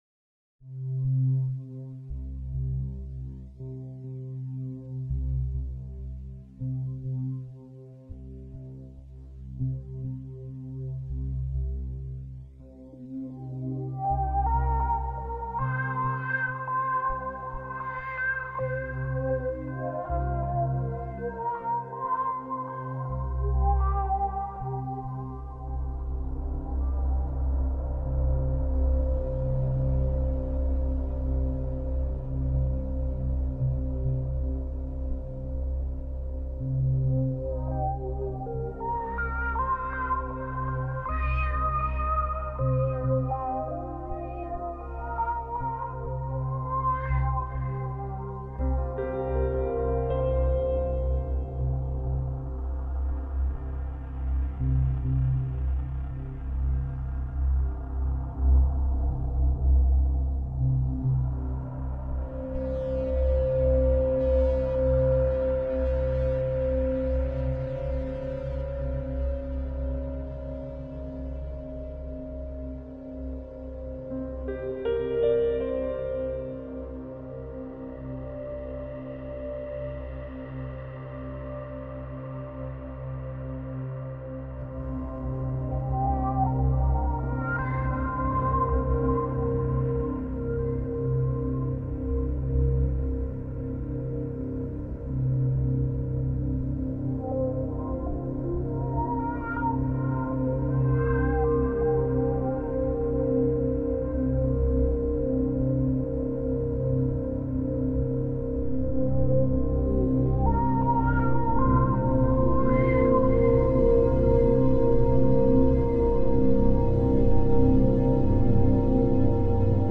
PASSAT (AMBIENT WAVE 2018)